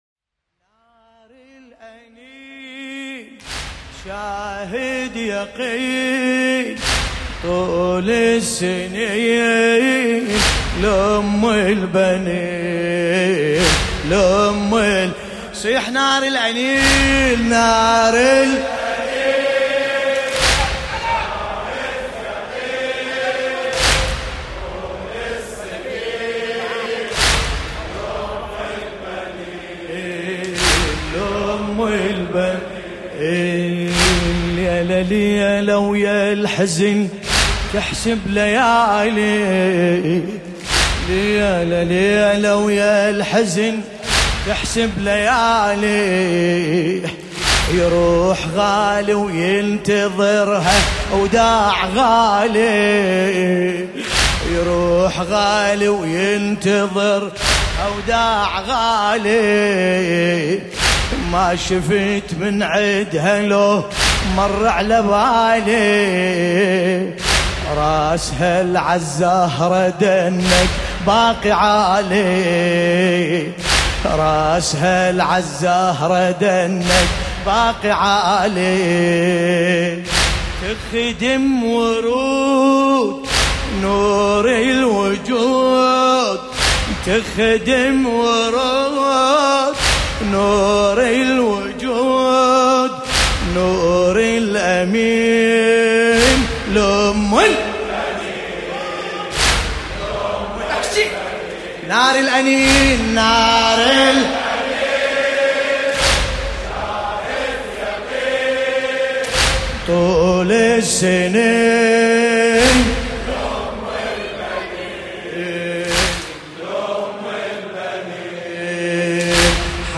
ملف صوتی نار الأنين بصوت باسم الكربلائي
الرادود : الحاج ملا باسم الكربلائی